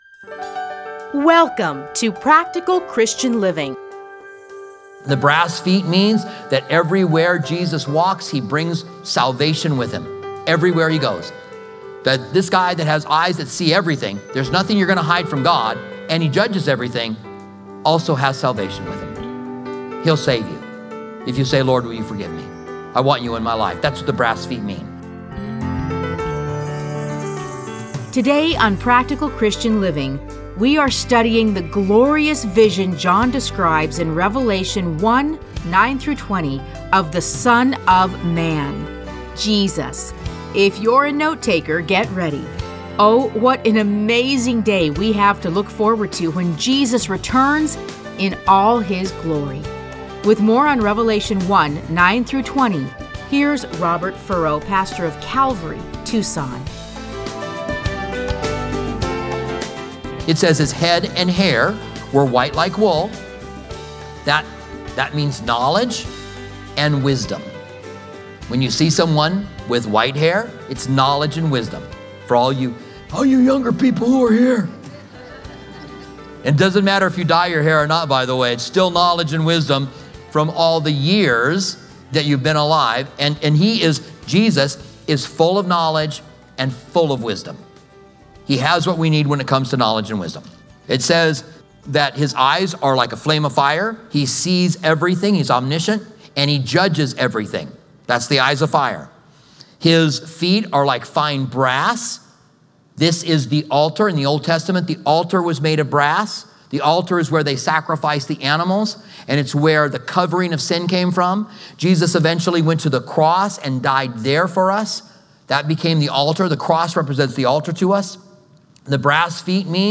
Listen to a teaching from Revelation 1:9-20.